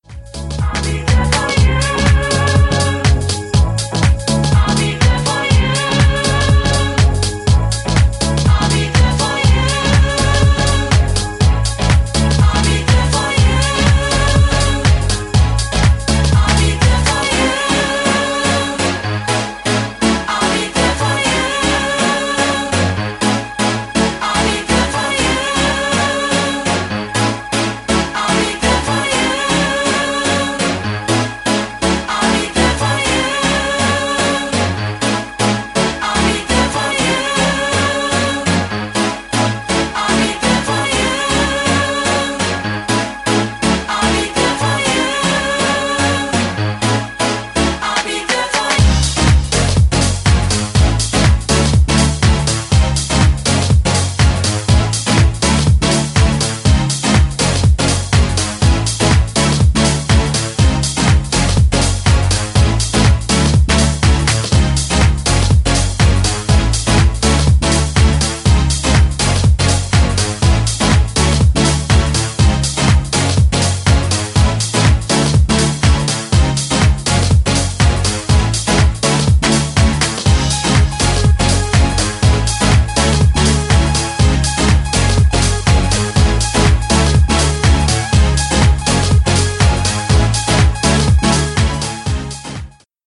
ソウルフル＆ディープなハウスで超超超おすすめの1枚！！
ジャンル(スタイル) HOUSE / SOULFUL HOUSE